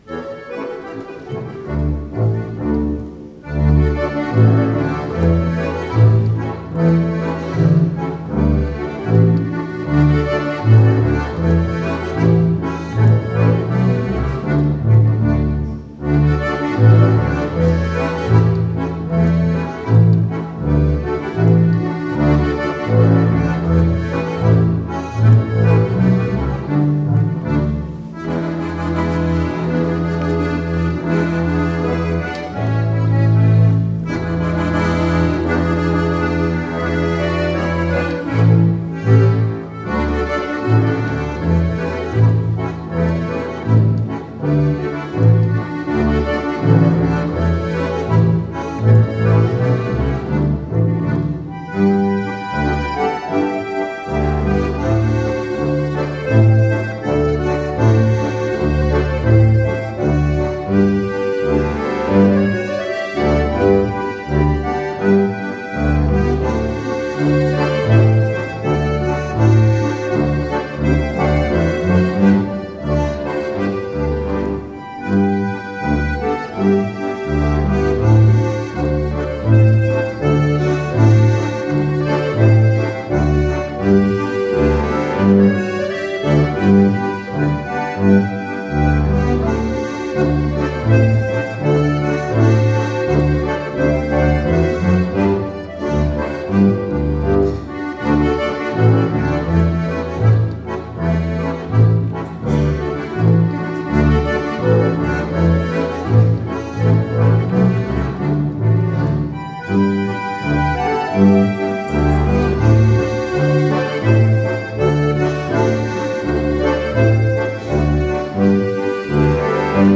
Wir öffneten das vierte Fenster unserer Advent-Galerie hinter dem Altar.
Ziehharmonika, Gitarre und Kontrabass ein sehr angenehmes und familiäres Flair